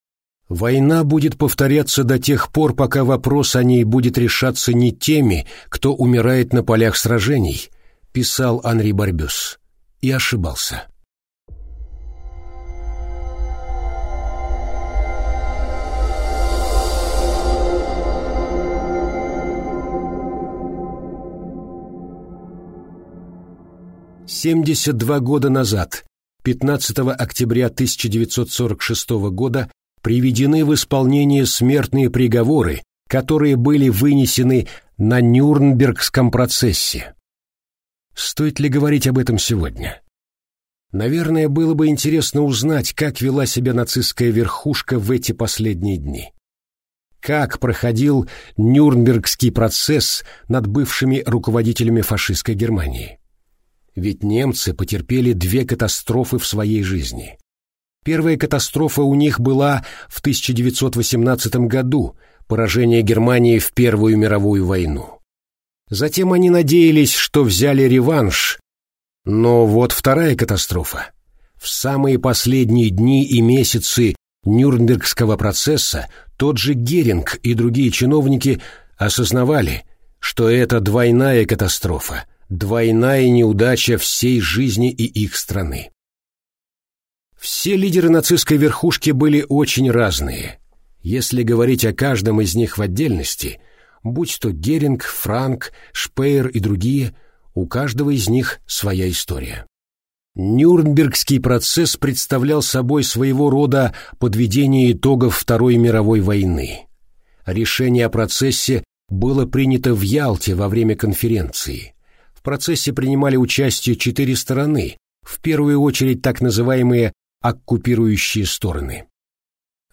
Аудиокнига Лица войны | Библиотека аудиокниг
Прослушать и бесплатно скачать фрагмент аудиокниги